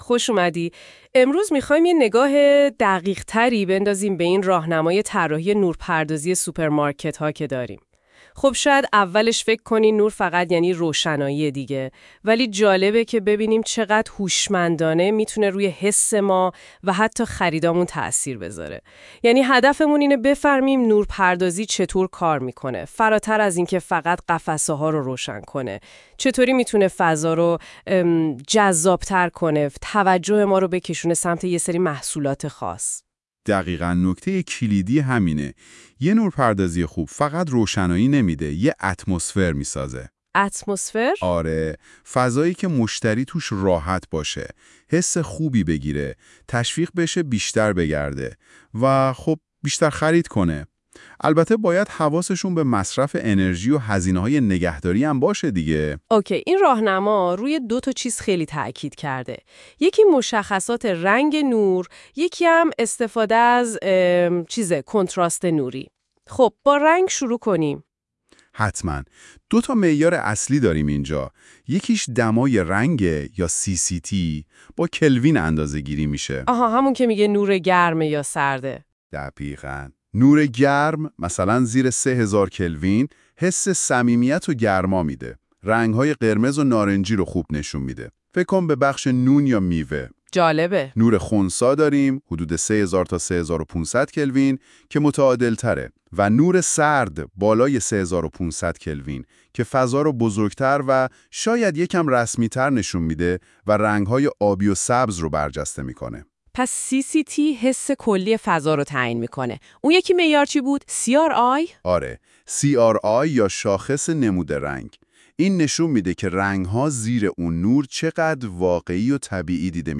📢 🔊 وویس آموزشی: نکات کلیدی نورپردازی سوپرمارکت